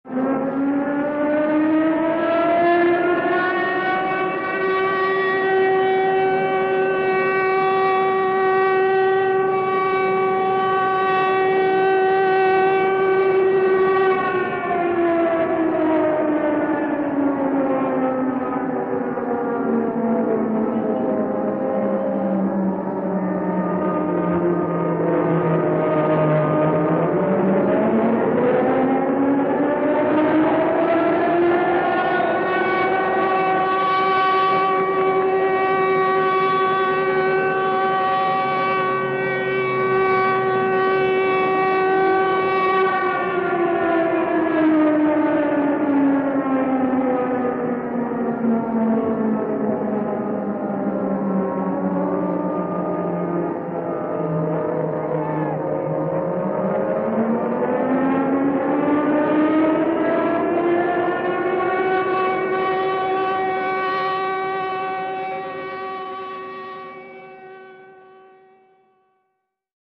Звуки военной тревоги
На этой странице собраны звуки военной тревоги: сирены, сигналы оповещения и другие тревожные аудиофайлы.